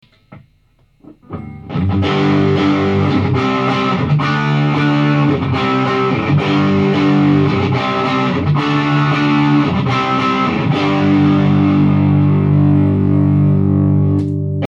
録音したMDから雑音のひどいものを取り除き、アップだば。
ロデオ・ドライヴはディストーションモードだば。